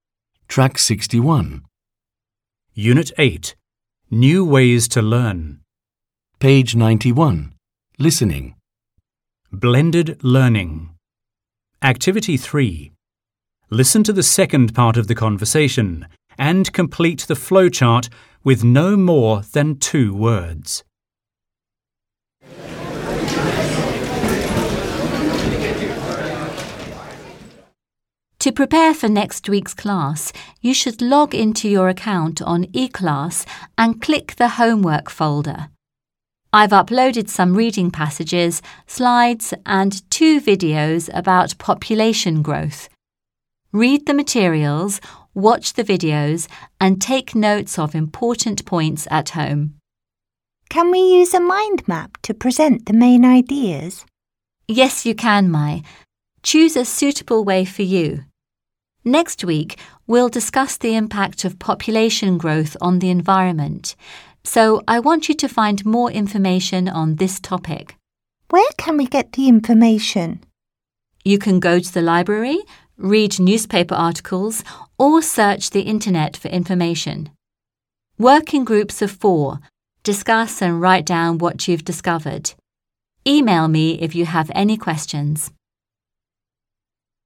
3. Task 3: Listen to the second part of the conversation and complete the flow chart with no more than TWO words.